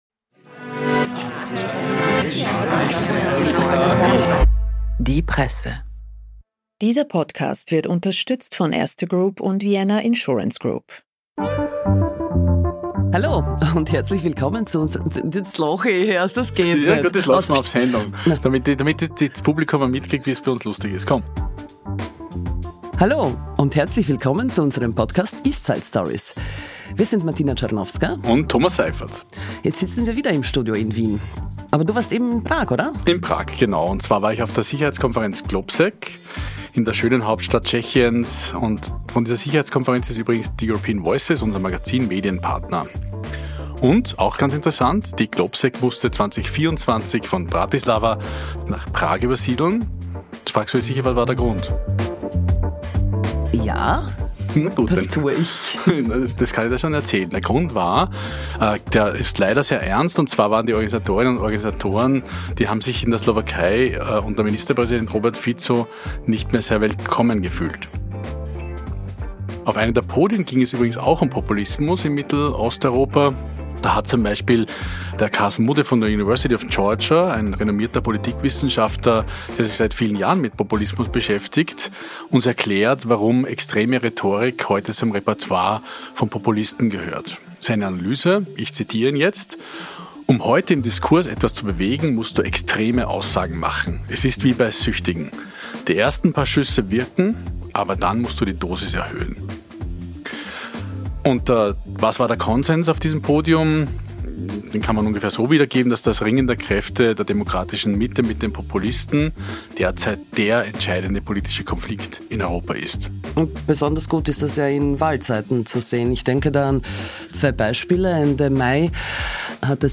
Plus eine Voice Message aus Warschau.